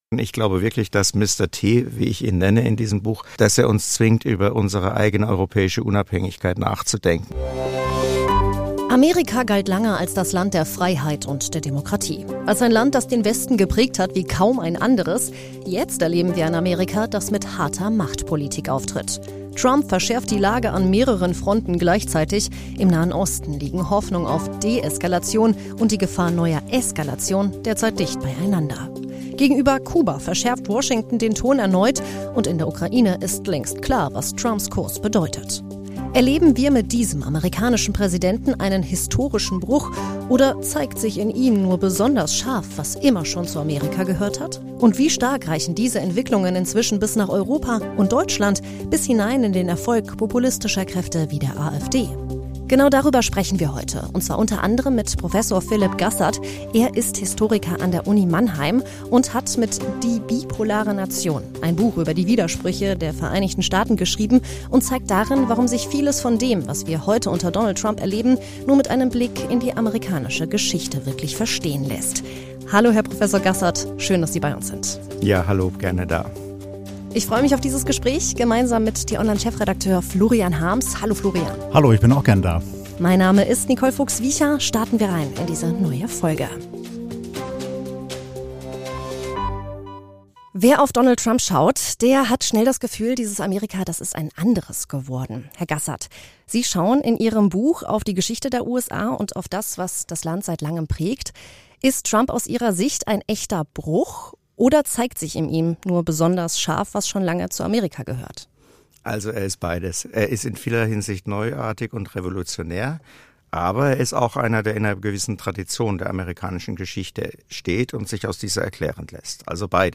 Das Gespräch bleibt aber nicht bei Amerika stehen. Es schaut auch darauf, was diese Verschiebungen für Europa bedeuten, bis hinein in die Debatten über Populismus und den Erfolg von Parteien wie der AfD.